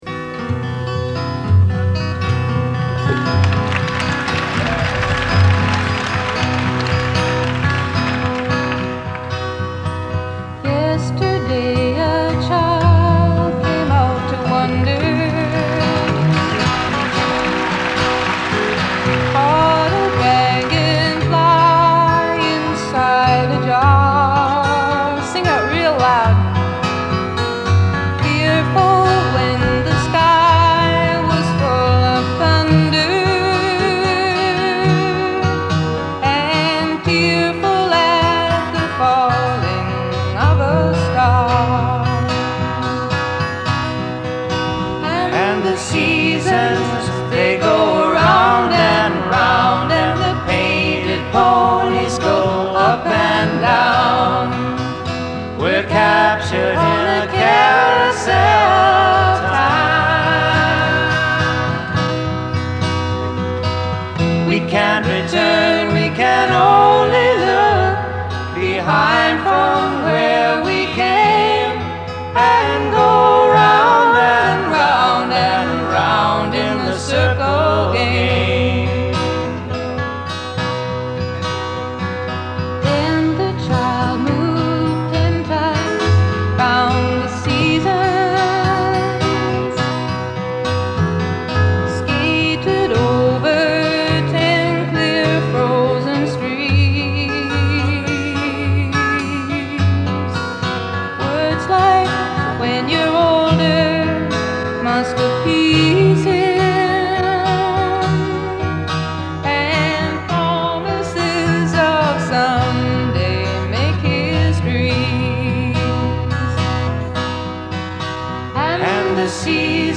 the 1970 concert that launched Greenpeace